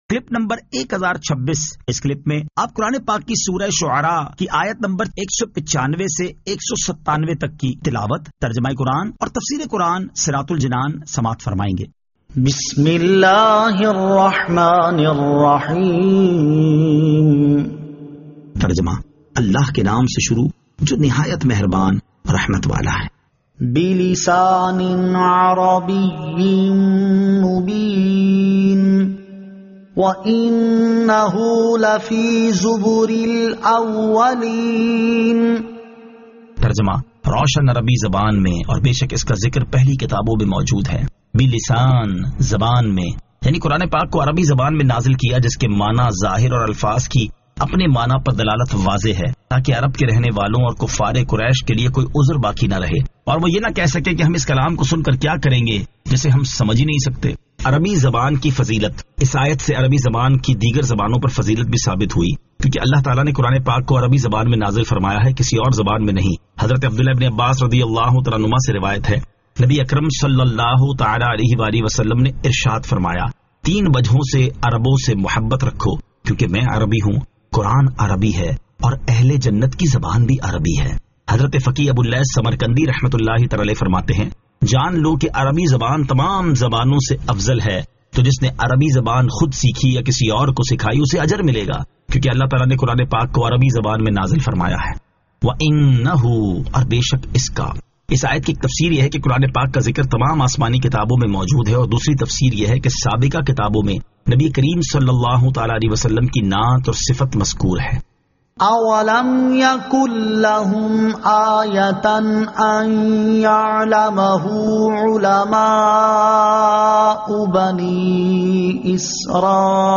Surah Ash-Shu'ara 195 To 197 Tilawat , Tarjama , Tafseer